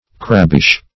Meaning of crabbish. crabbish synonyms, pronunciation, spelling and more from Free Dictionary.
Search Result for " crabbish" : The Collaborative International Dictionary of English v.0.48: Crabbish \Crab"bish\ (kr[a^]b"b[i^]sh), a. Somewhat sour or cross.
crabbish.mp3